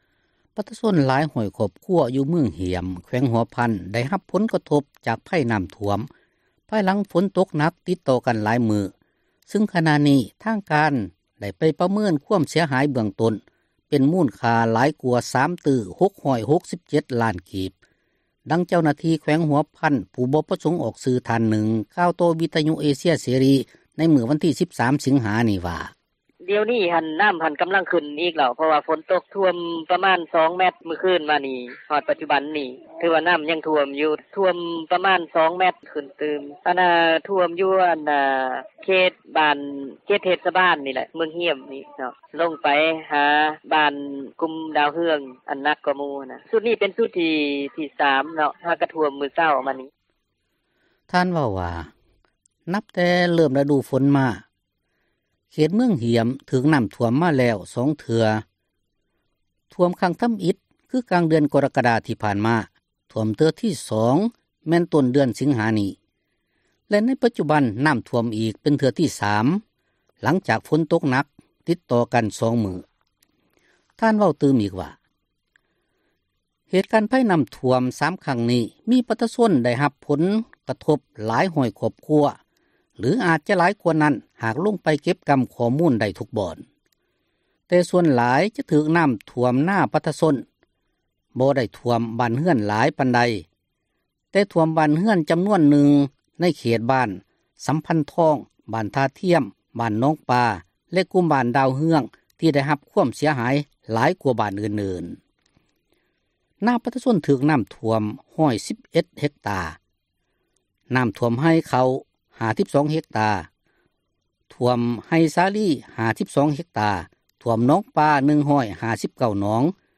ປະຊາຊົນຫລາຍຮ້ອຍຄອບຄົວ ຢູ່ເມືອງຮ້ຽມ ແຂວງຫົວພັນ ໄດ້ຮັບຜົລກະທົບຈາກພັຍນໍ້າຖ້ວມ ພາຍຫລັງຝົນຕົກໜັກຕິດຕໍ່ກັນຫຼາຍມື້ ຊຶ່ງ ໃນຂນະນີ້ ທາງການໄດ້ໄປປະເມີນຄວາມເສັຍຫາຍເບື້ອງຕົ້ນ ເປັນມູນຄ່າຫຼາຍກວ່າ 3 ຕື້ 667 ລ້ານກີບ; ດັ່ງເຈົ້າໜ້າທີ່ ແຂວງຫົວພັນ ຜູ້ບໍ່ປະສົງອອກຊື່ທ່ານນຶ່ງ ກ່າວຕໍ່ວິທຍຸເອເຊັຍເສຣີ ໃນມື້ວັນທີ 13 ສິງຫານີ້ວ່າ: